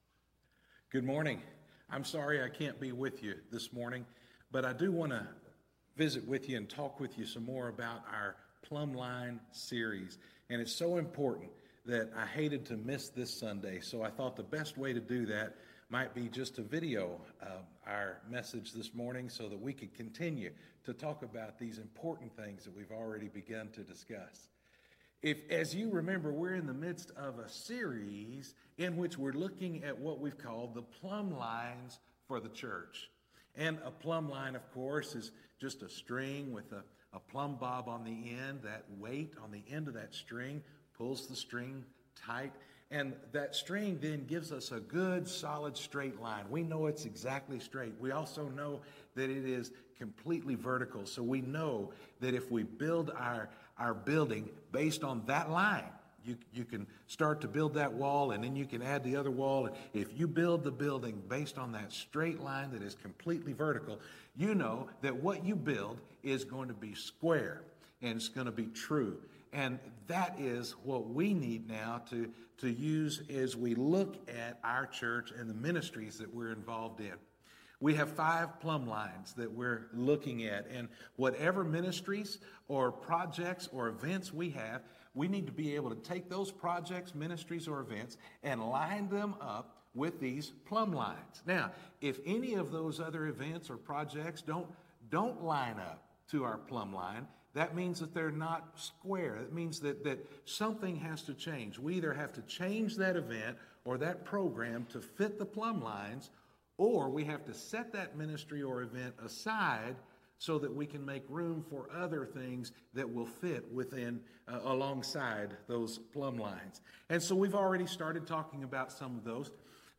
This week's Plumb Line message was videoed earlier in the week and then played back during worship on Sunday, so the introduction may be a little confusing.